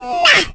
Below lies a collection of voice clips and sound effects from the first in the Mario Galaxy series!